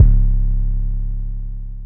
atl 808.wav